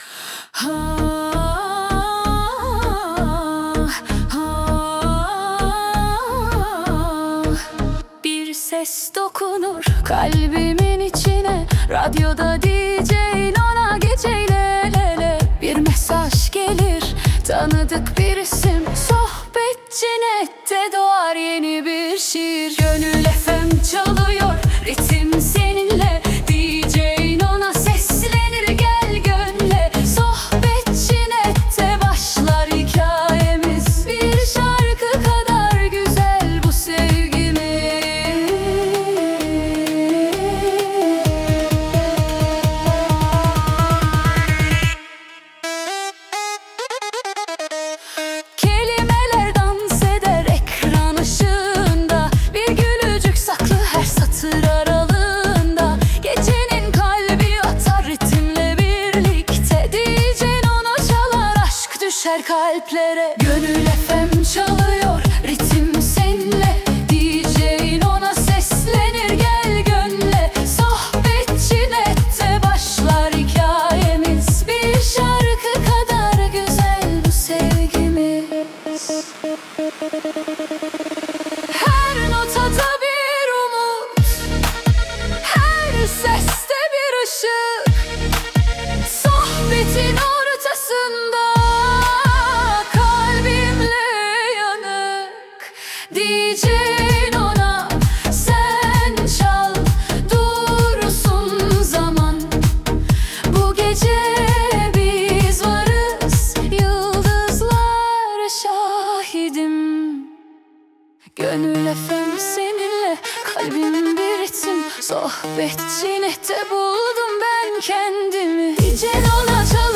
🎤 Vokalli 18.10.2025